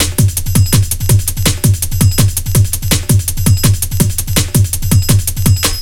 Index of /90_sSampleCDs/Zero-G - Total Drum Bass/Drumloops - 3/track 51 (165bpm)